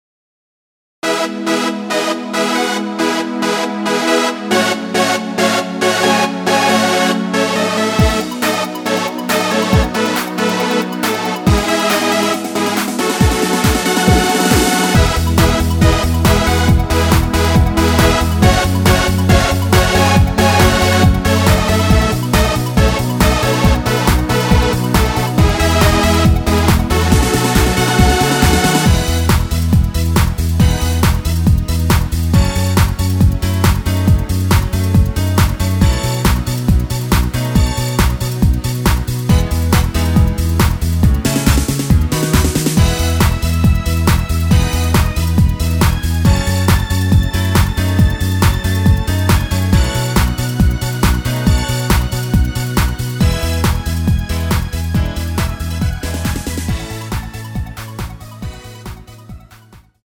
내린 MR 입니다.
앞부분30초, 뒷부분30초씩 편집해서 올려 드리고 있습니다.
중간에 음이 끈어지고 다시 나오는 이유는